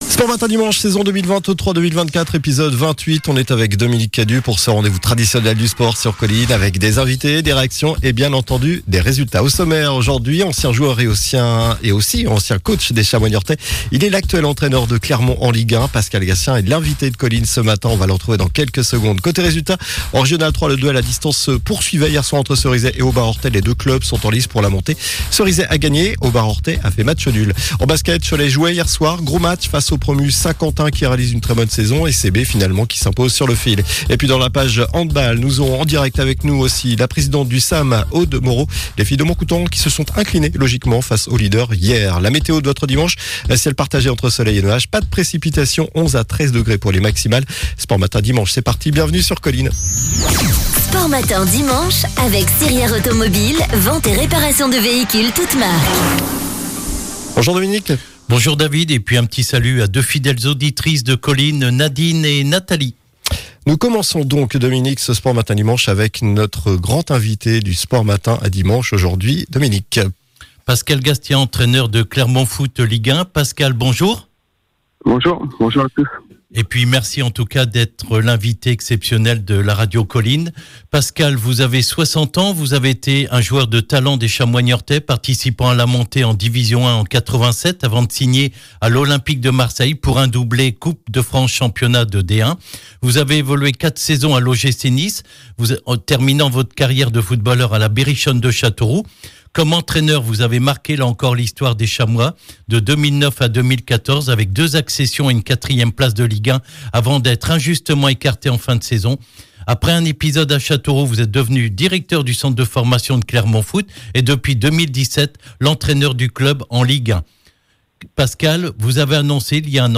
nous aurons en direct avec nous